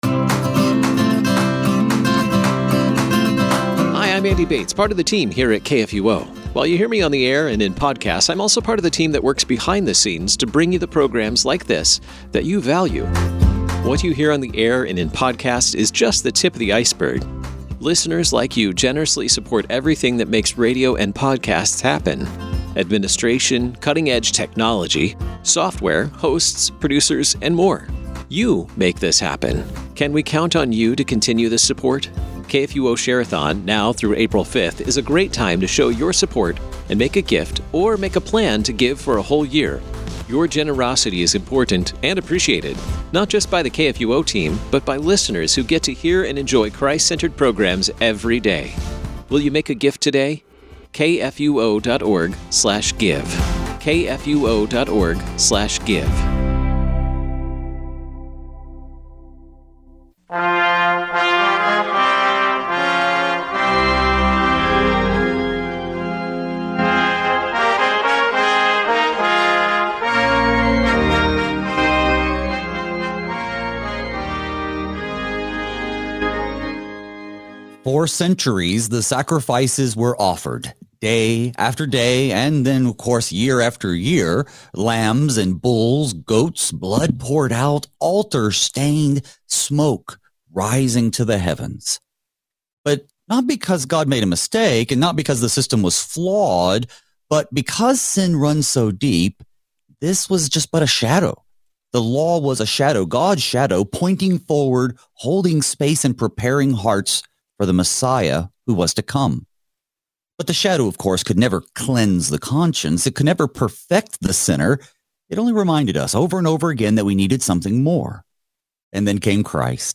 Thy Strong Word reveals the light of our salvation in Christ through study of God’s Word, breaking our darkness with His redeeming light. Each weekday, two pastors fix our eyes on Jesus by considering Holy Scripture, verse by verse, in order to be strengthened in the Word and be equipped to faithfully serve in our daily vocations.